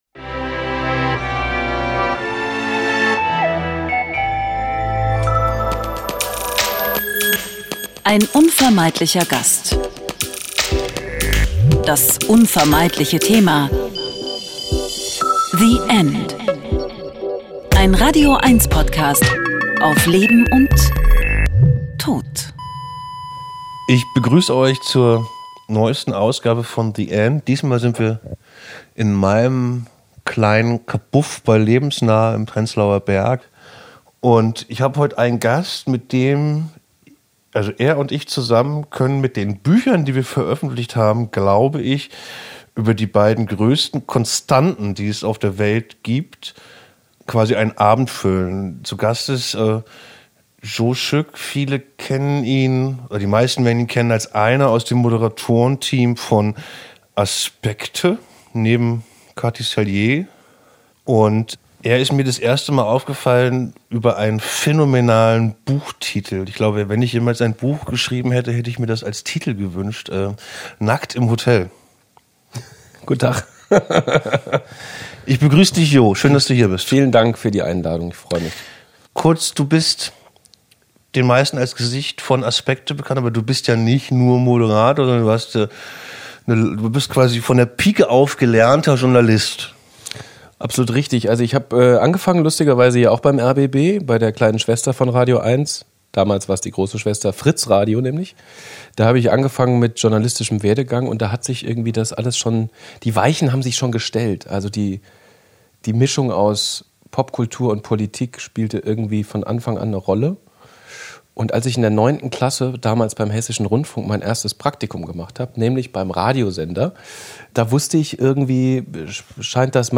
Ein Gespräch über körperliche Schmerzen in der Trauer und das Wunder des Lebens.